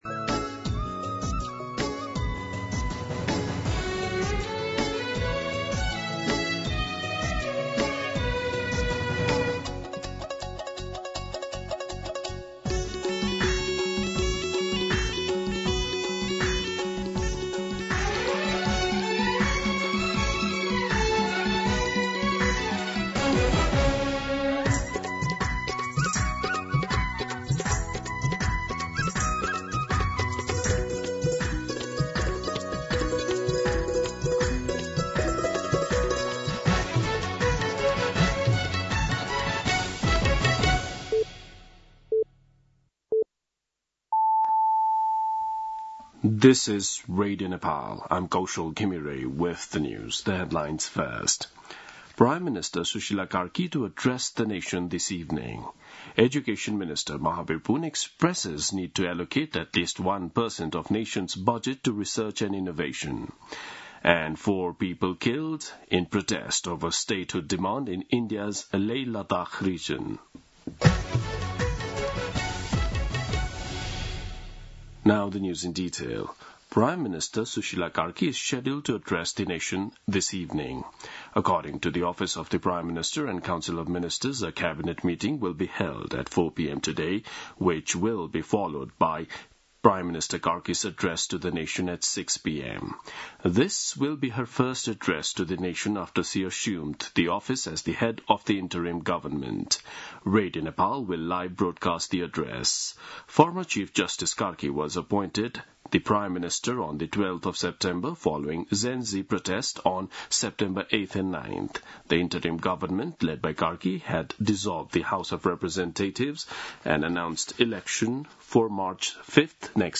दिउँसो २ बजेको अङ्ग्रेजी समाचार : ९ असोज , २०८२